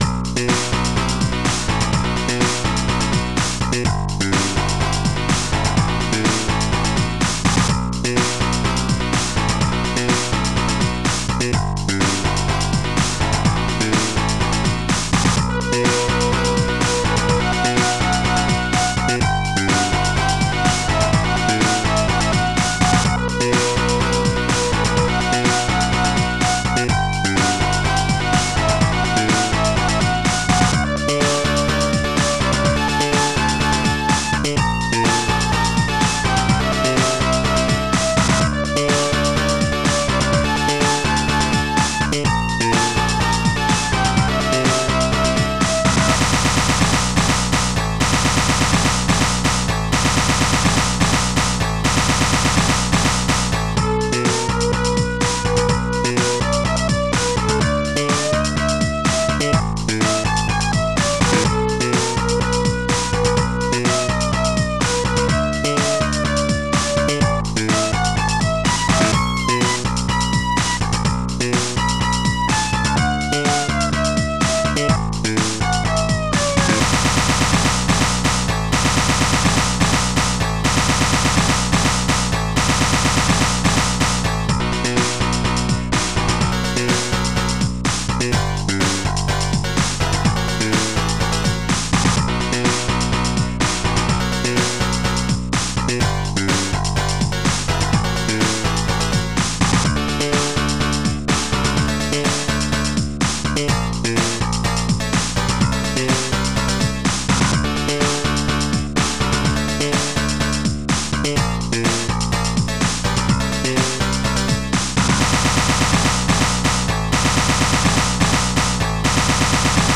SoundTracker Module